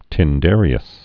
(tĭn-dârē-əs)